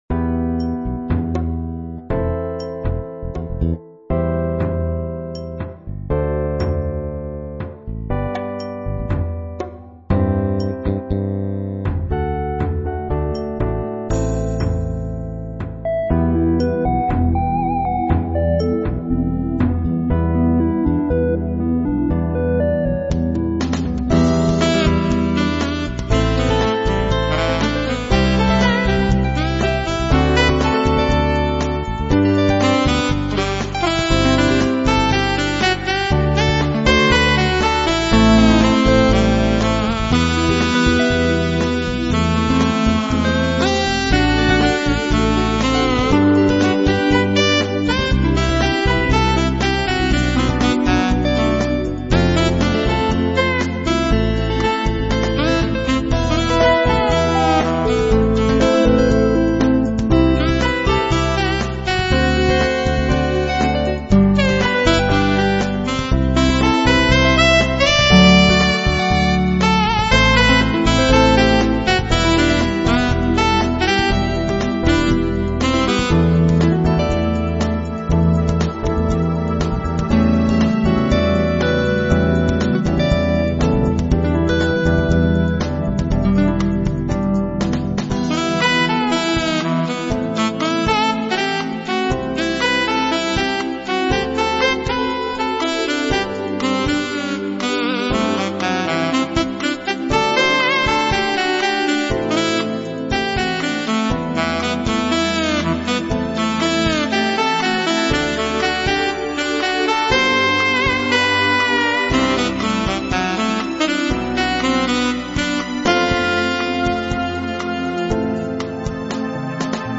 Soft Dramatic Soundtrack Music with Synth Saxophone lead